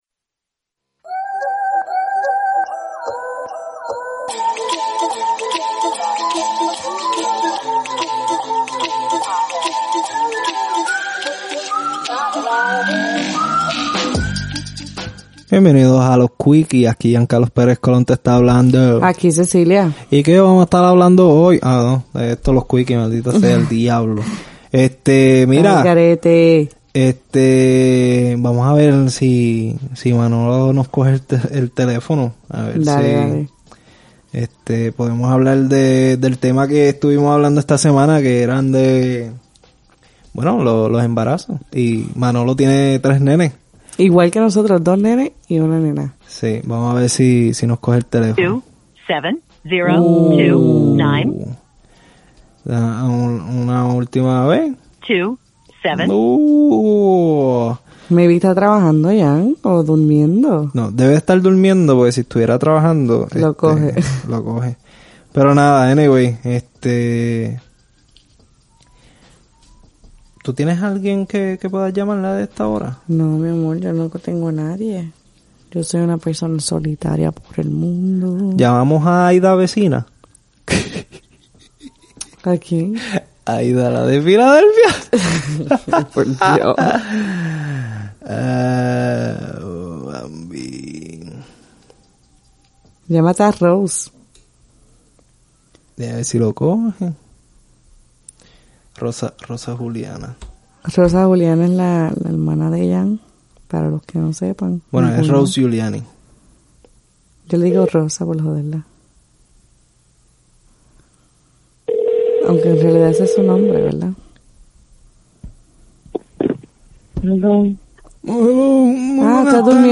En este quickie decidimos hacer varias llamadas para conversar acerca del tema que estuvimos hablando en nuestro pasado episodio de trapitos sucios. Nos encanta escuchar y conocer las distintas experiencias.